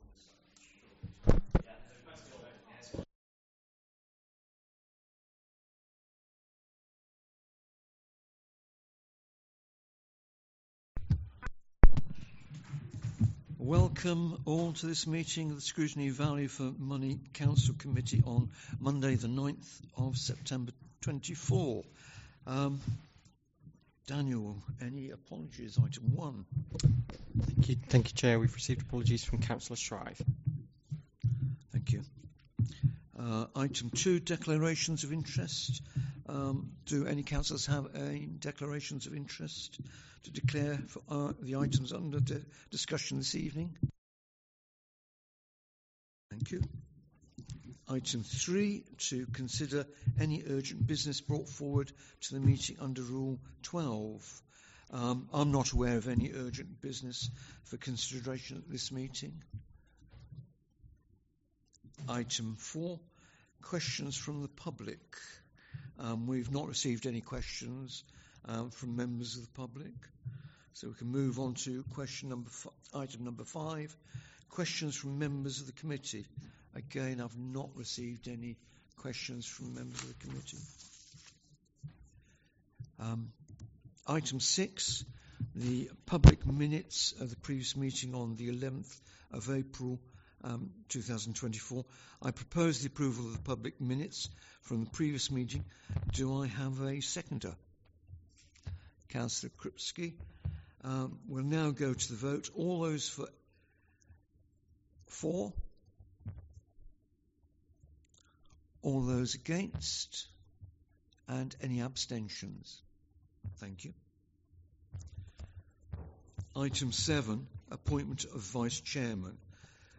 Committee Scrutiny Value for Money Council Committee Meeting Date 09-09-24 Start Time 6.30pm End Time 7.21pm Meeting Venue Coltman VC Room, Town Hall, Burton upon Trent Please be aware that not all Council meetings are live streamed.